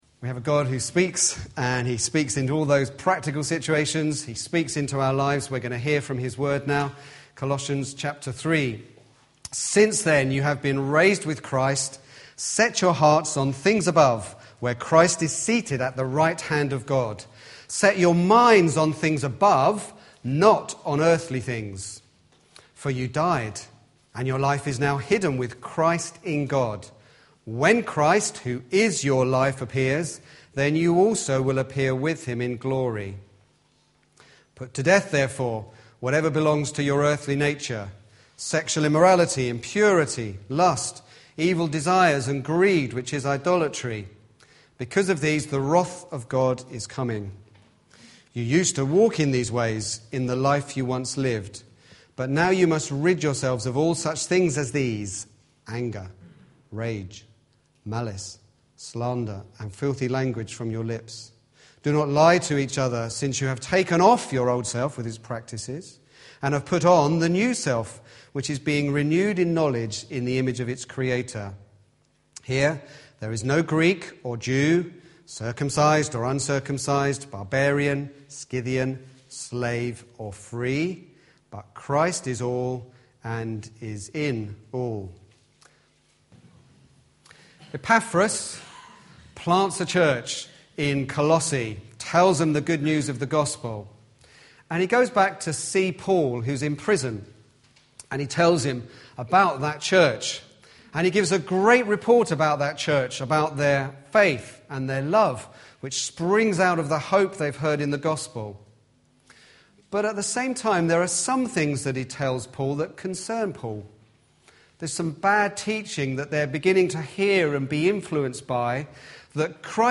An independent evangelical church